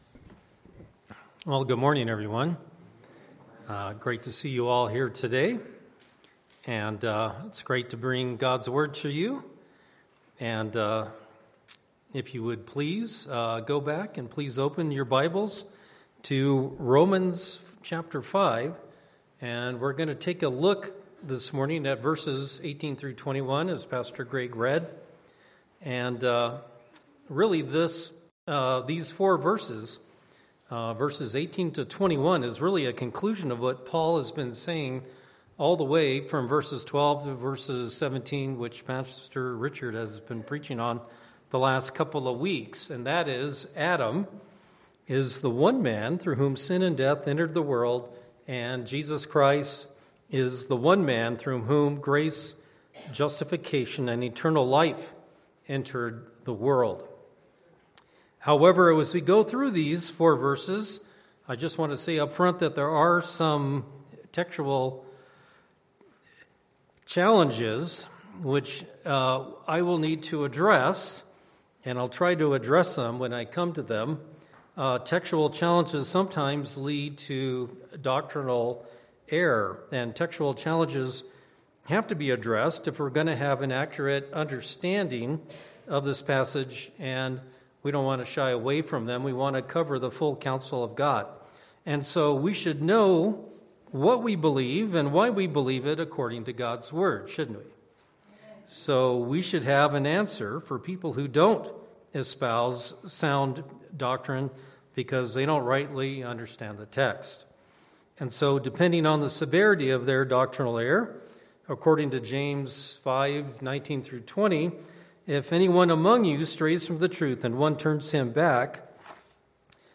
Passage: Romans 5:18-21 Service Type: Sunday Morning Worship « Death Through Adam and Life Through Jesus Dead to Sin